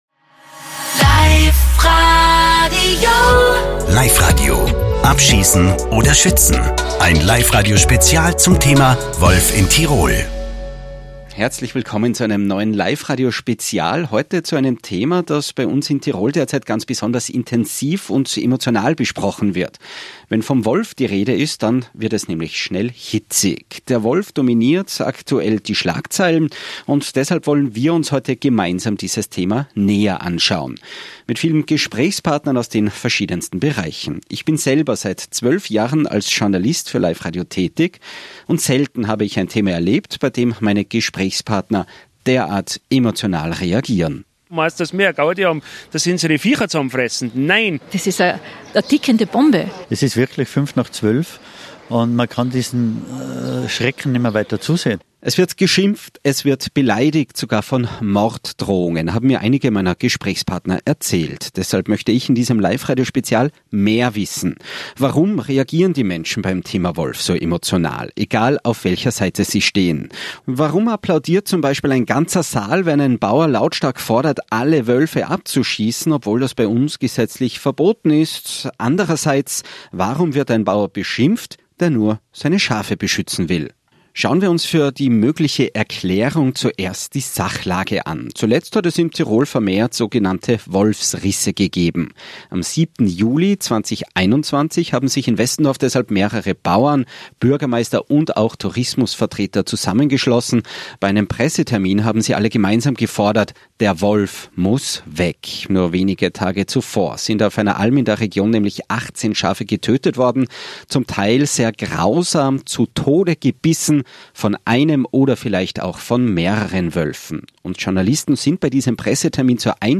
An dieser Stelle wollen wir nun beide Seiten zu Wort kommen zu lassen.